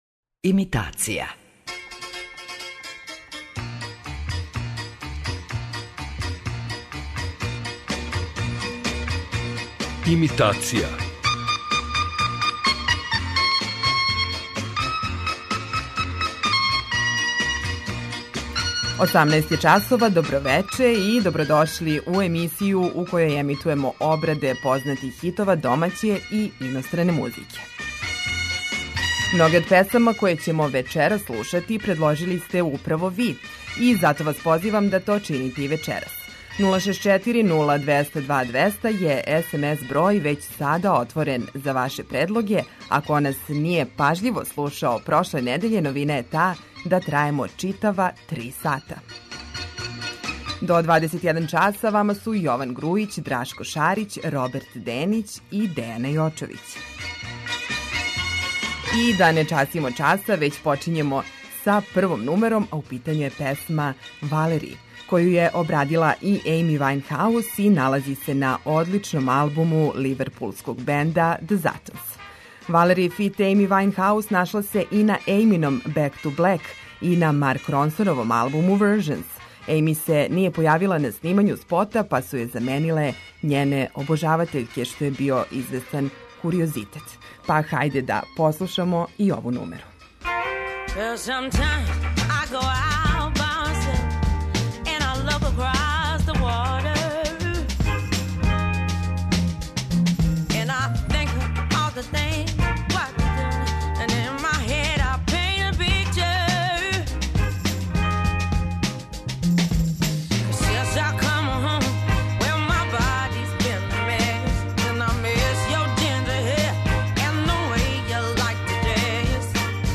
Имитација је емисија у којој емитујeмо обраде познатих хитова домаће и иностране музике.